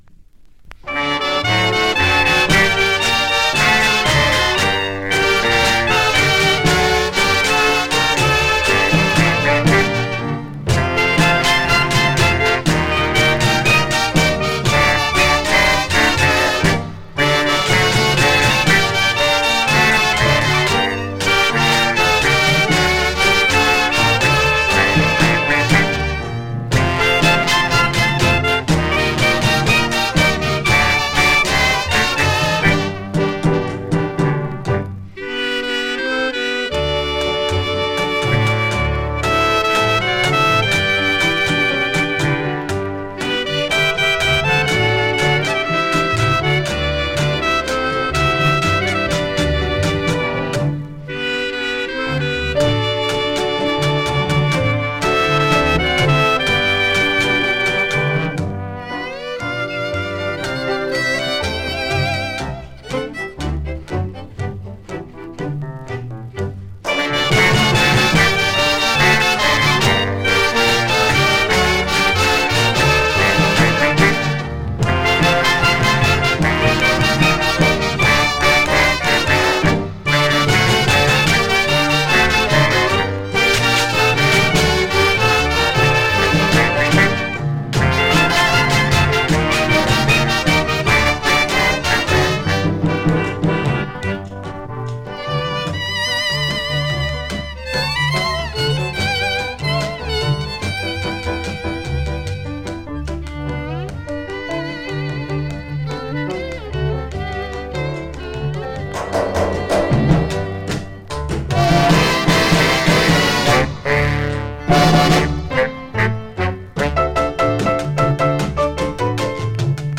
Just remember, the melody tends to heat up at the end.